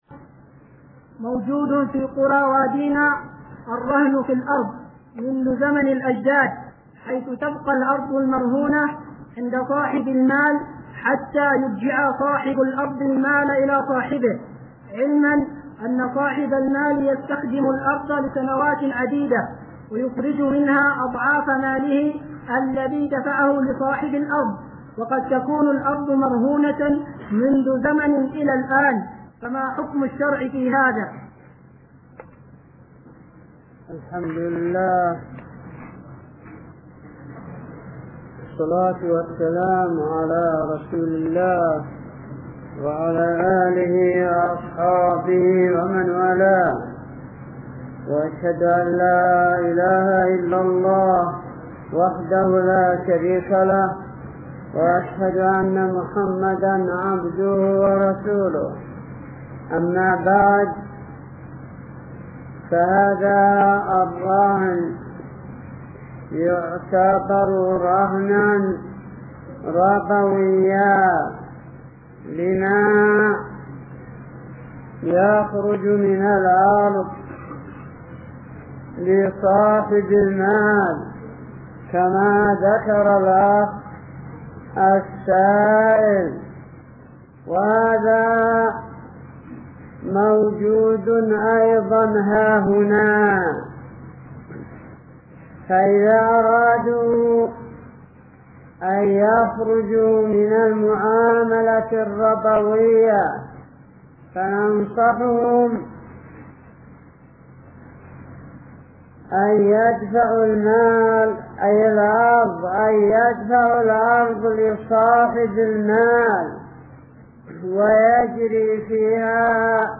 من شريط : ( أسئلة الأخوة بوادي بن علي بحضرموت )